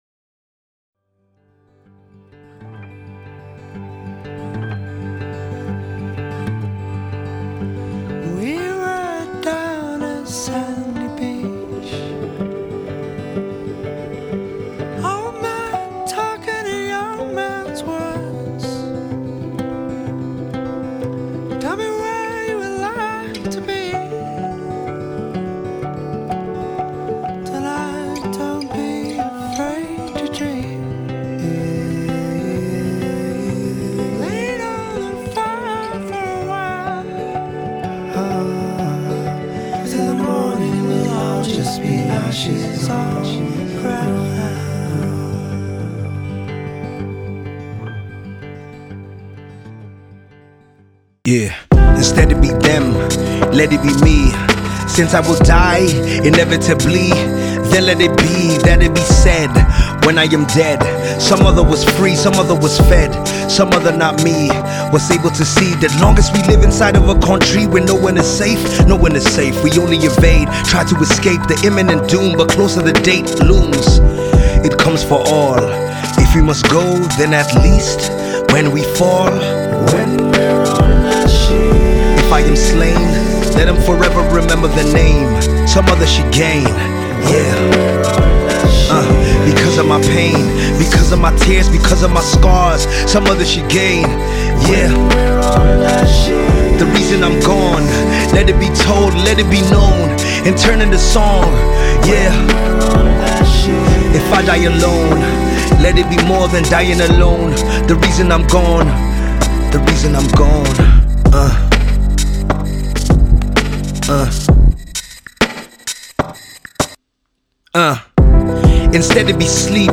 Opening sample